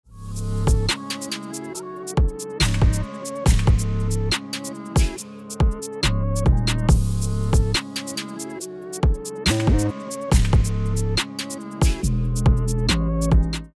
▼Genre : Drillで生成されたサンプル例
従来なら個別に探す必要があった各パートのサンプルが、このようにわずか数クリックでジャンルに最適化されて生成されるのは画期的です。
Genre-Drill.mp3